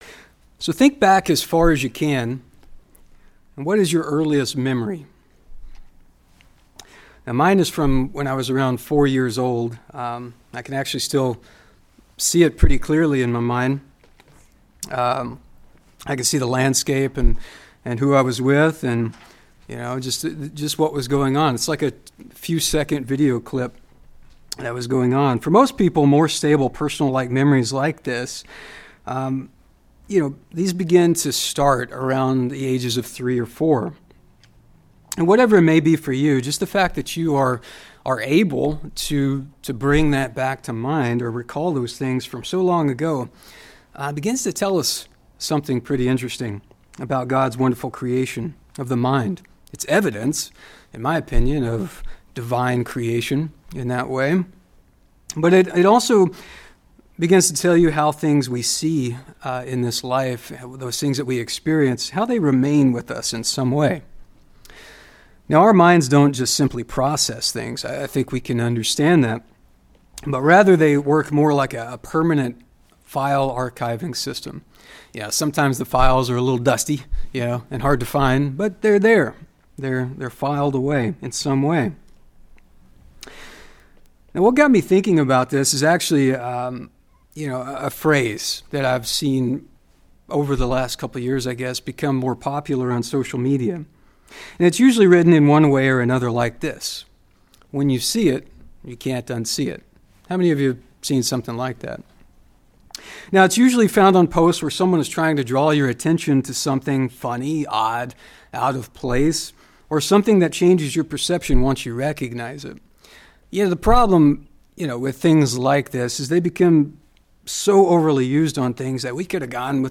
Sermons
Given in Spokane, WA Kennewick, WA Chewelah, WA